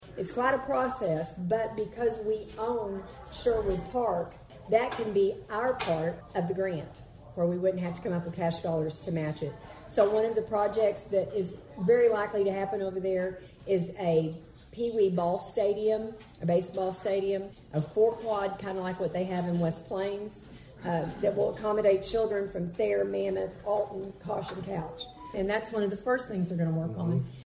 Thayer Mayor Donna Martin speaks at Rotary on Wednesday